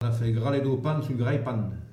Mots Clé pain ; accessoire(s) diver(s) ; Localisation Saint-Jean-de-Monts
Catégorie Locution